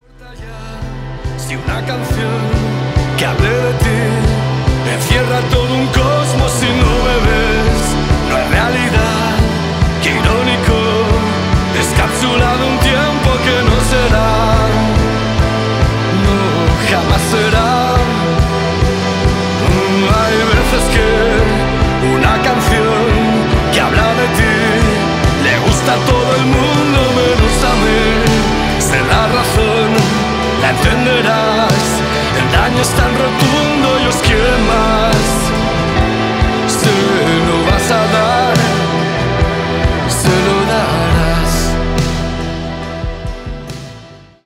indie rock e indie pop